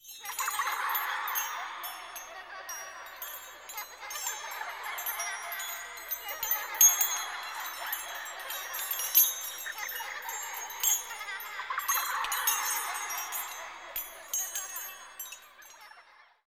Звук смеющихся снежинок для театра